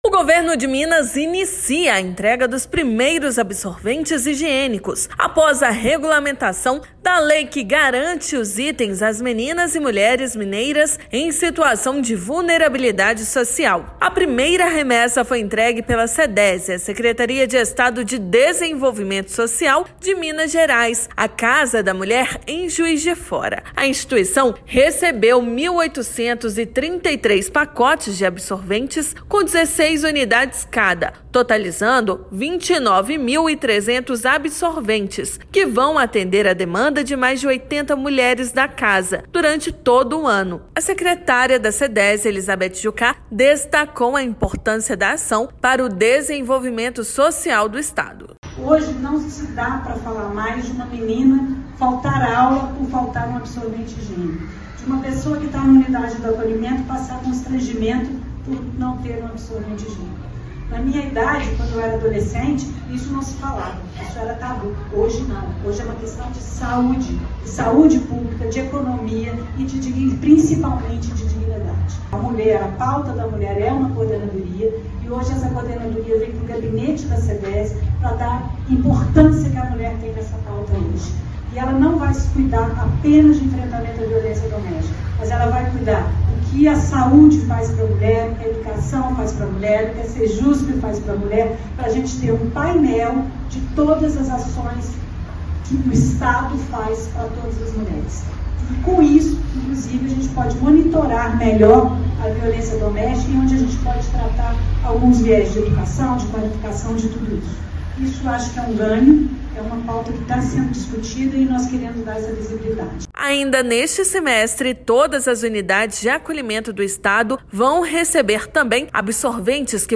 Entrega atende à lei que garante os itens para mulheres em situação de vulnerabilidade social. Ouça matéria de rádio.